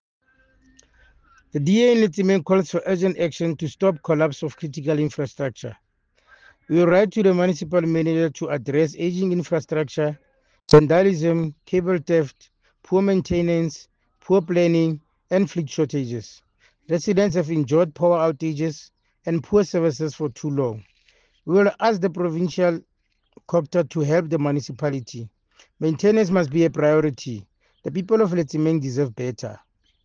English soundbite by Cllr Thabo Nthapo,